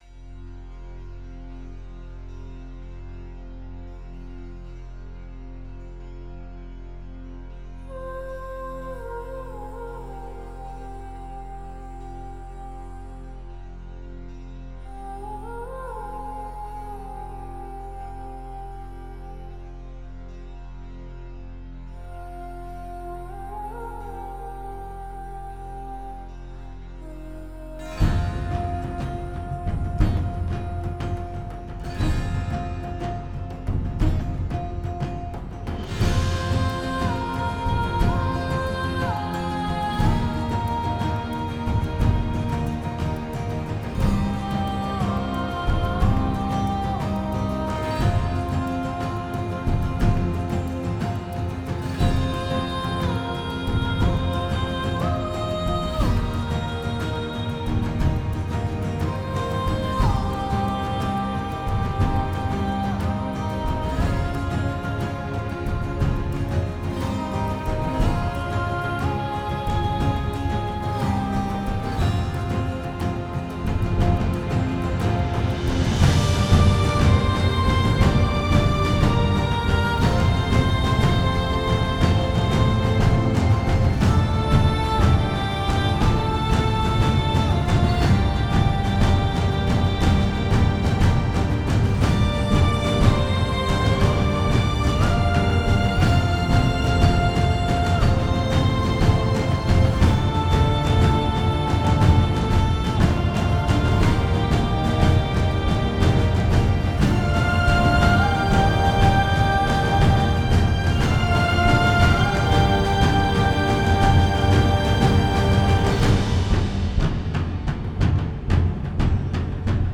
(Musica tema)
trilha sonora orquestrada